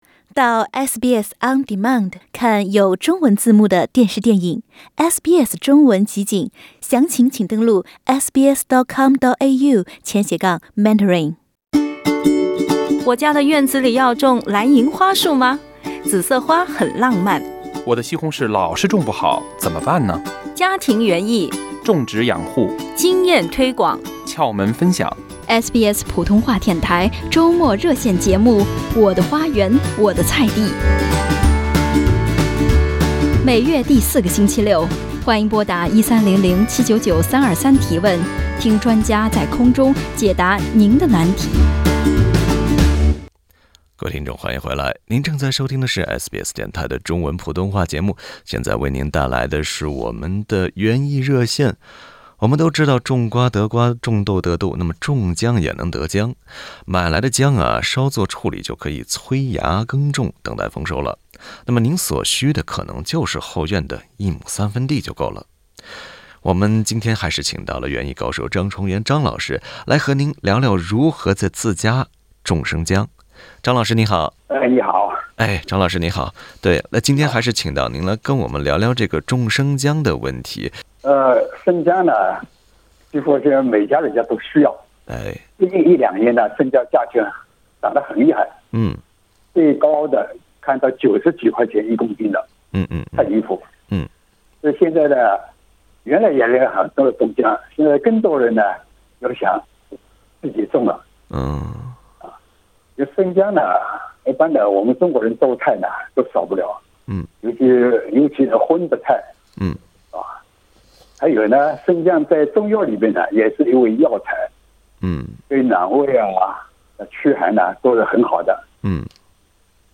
欢迎您点击图片音频收听完整的采访， 请在 Facebook 和 Twitter 关注SBS中文，了解更多澳洲新闻。